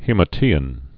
(hēmə-tēĭn, hēmə-tēn)